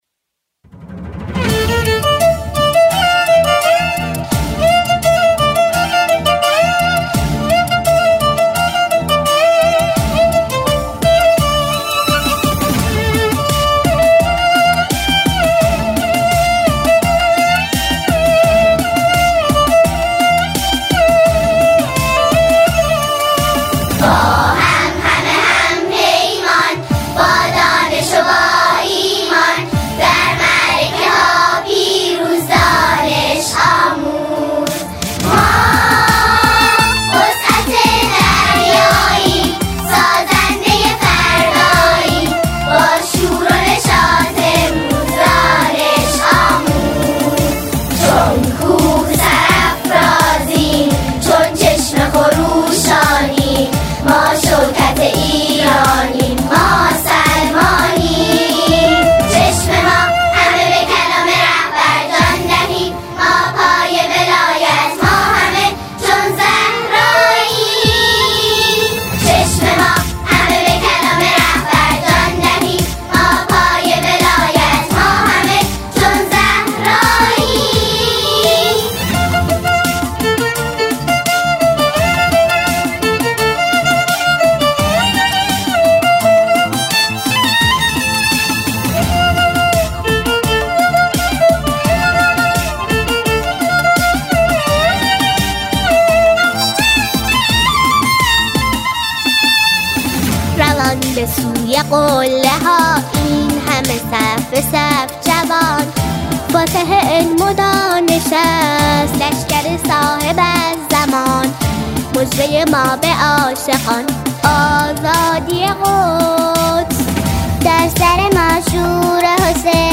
یک سرود حماسی و پرانرژی
ژانر: سرود